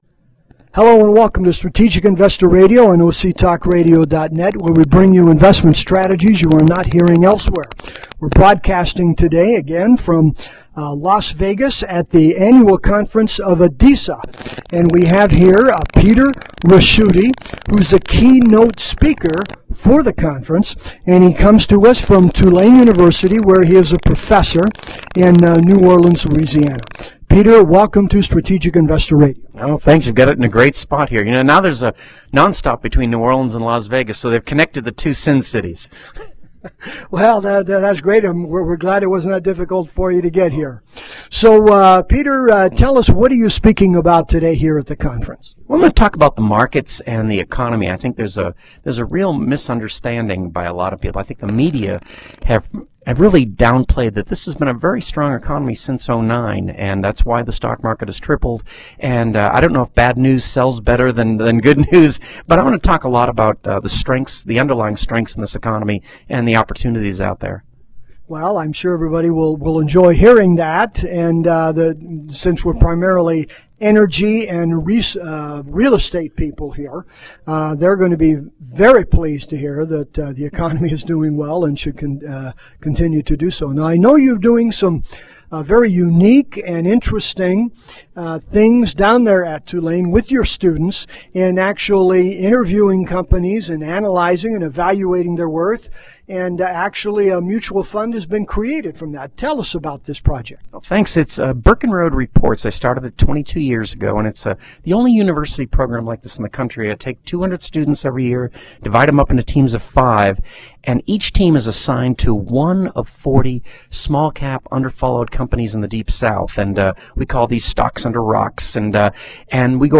This interview is not an offer to sell, nor an endorsement of, the fund.